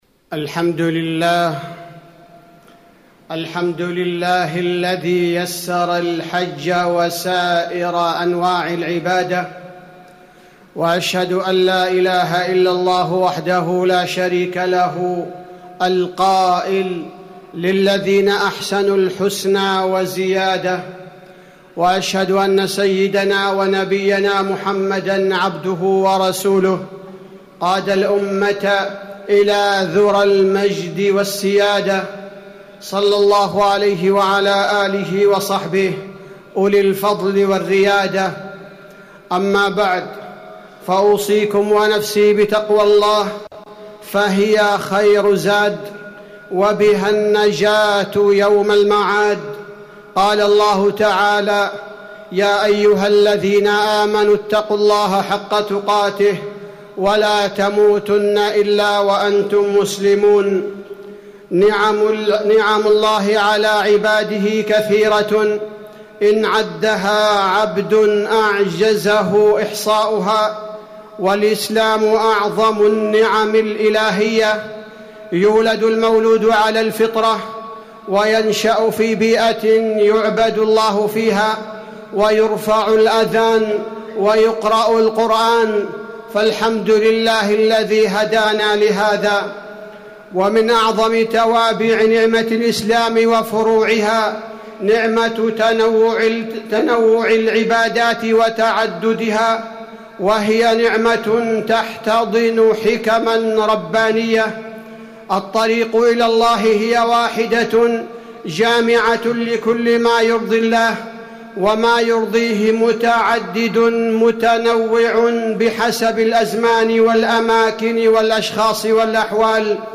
تاريخ النشر ٢٧ ذو الحجة ١٤٣٩ هـ المكان: المسجد النبوي الشيخ: فضيلة الشيخ عبدالباري الثبيتي فضيلة الشيخ عبدالباري الثبيتي فضائل العبادات وتنوعها